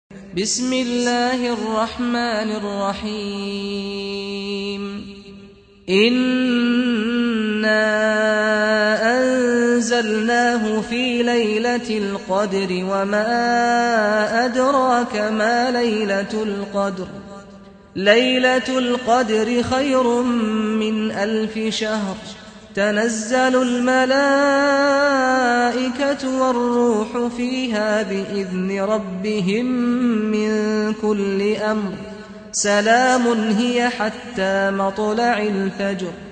سُورَةُ القَدۡرِ بصوت الشيخ سعد الغامدي